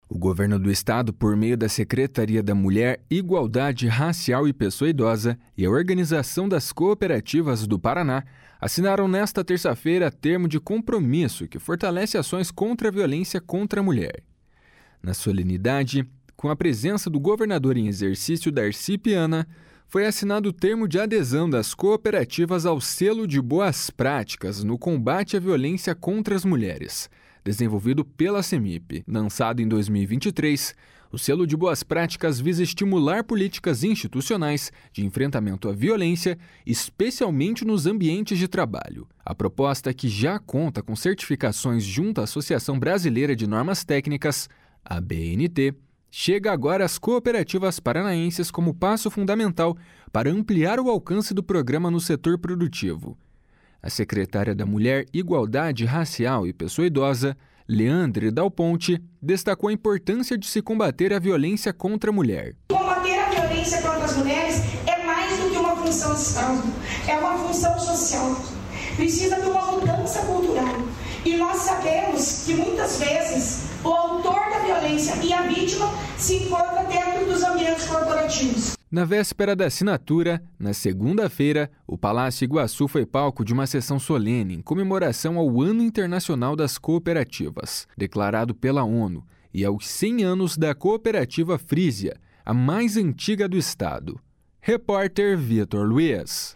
A secretária da Mulher, Igualdade Racial e Pessoa Idosa, Leandre Dal Ponte, destacou a importância de se combater a violência contra a mulher. // SONORA LEANDRE DAL PONTE //